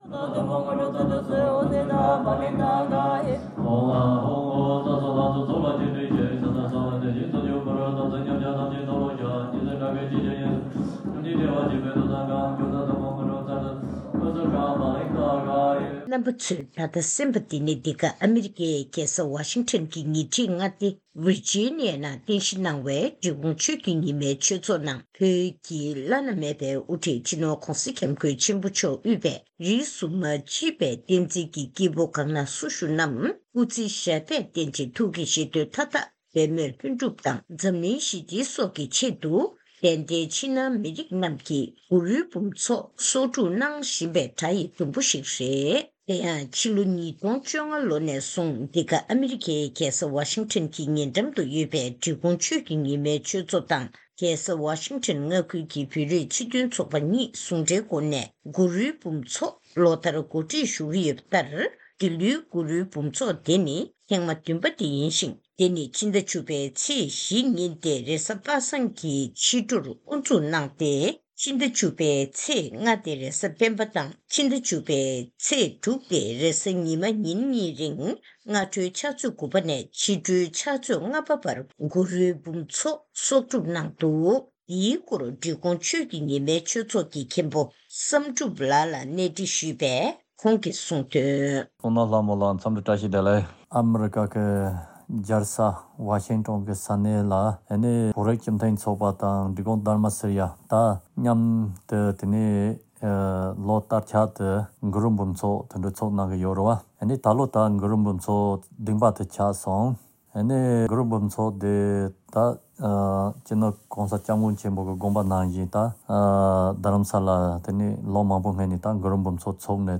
ཐེངས་འདིའི་བཅར་འདྲིའི་ལེ་ཚན